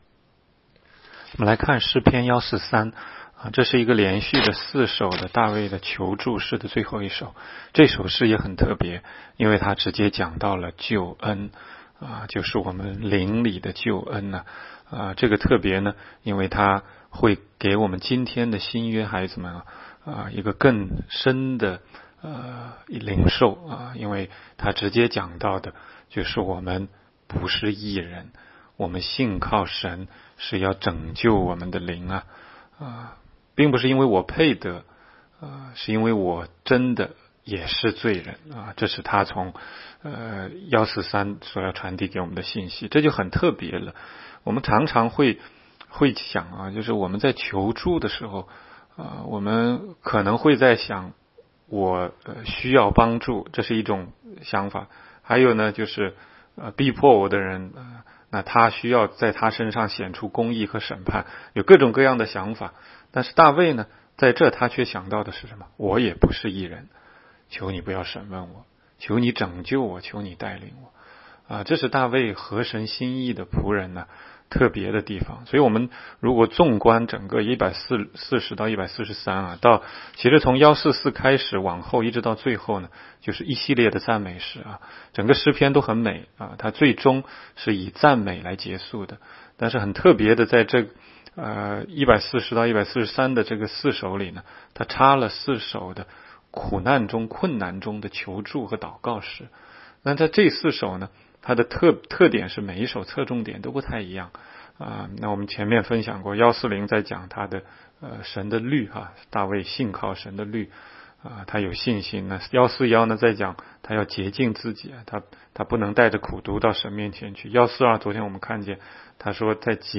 16街讲道录音 - 每日读经 -《 诗篇》143章